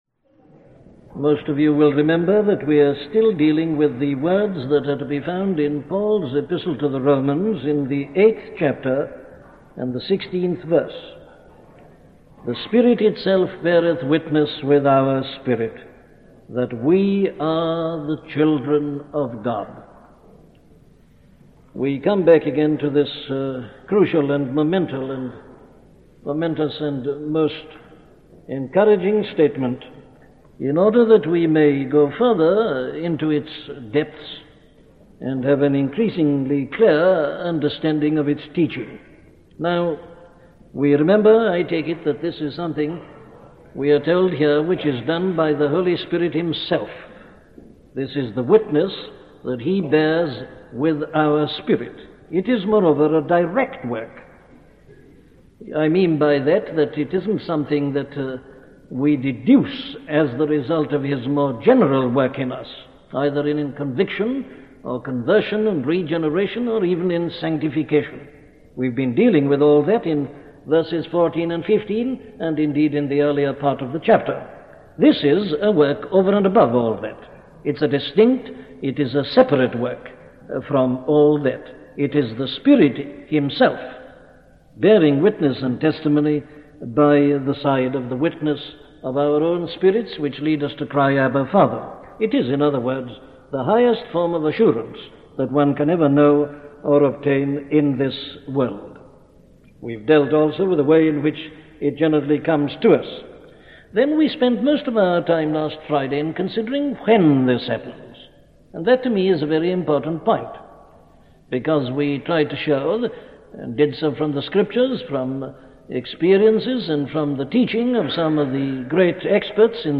Free Sermon | Sermons on Romans 8 | Page 2 of 4
A collection of sermons on Sermons on Romans 8 by Dr. Martyn Lloyd-Jones